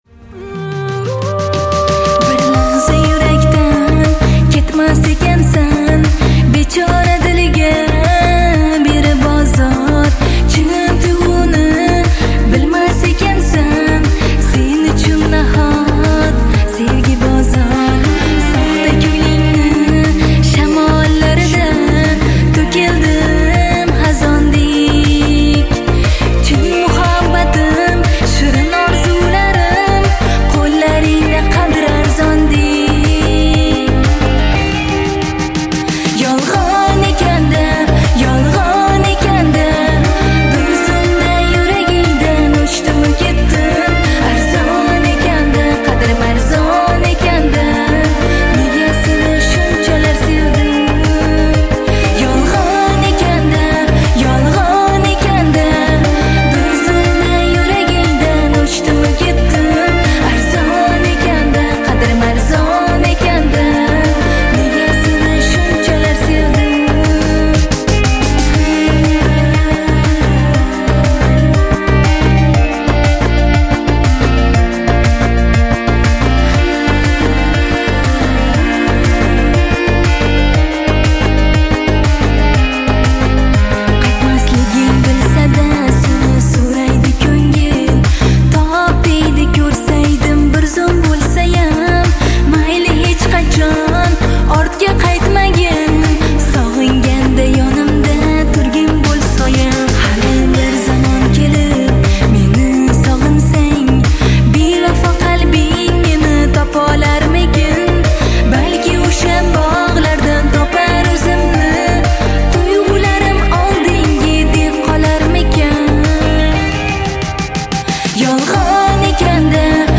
• Жанр: Таджикские Песни